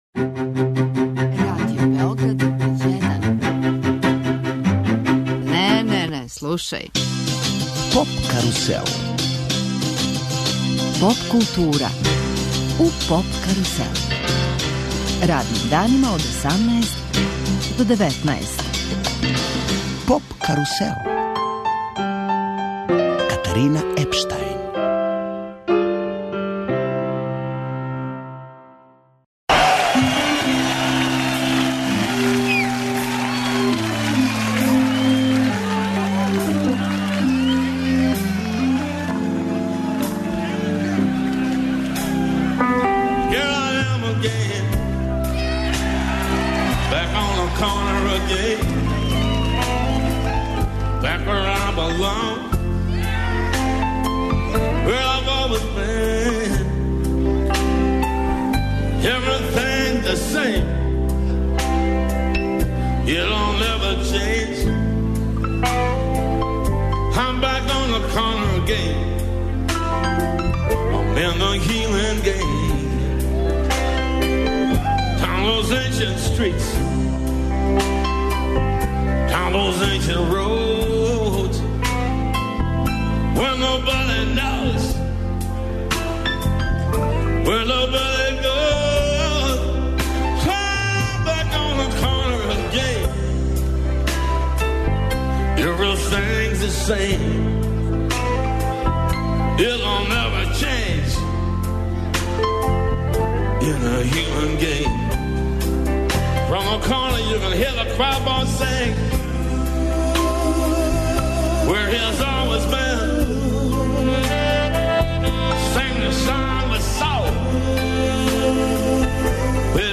Емисија из домена популарне културе.